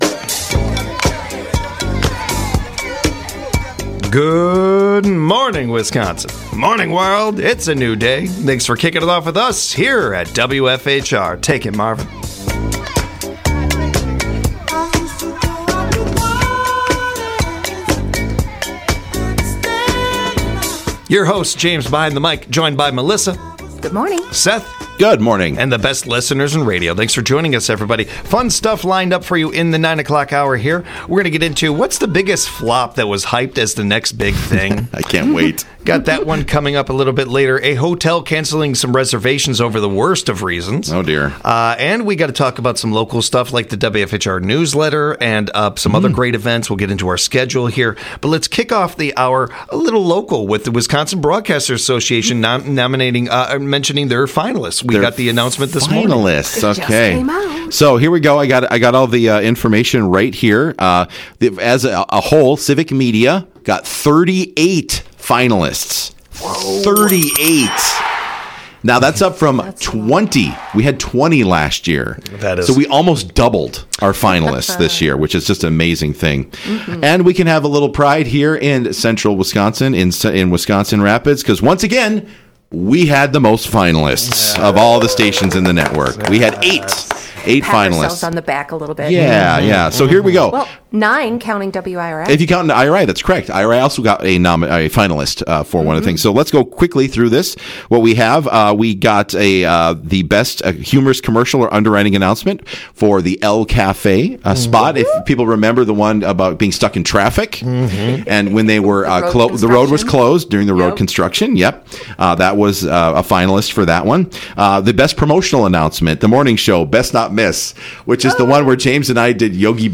They share some historical music, and reconstructed musical instruments.